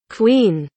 queen kelimesinin anlamı, resimli anlatımı ve sesli okunuşu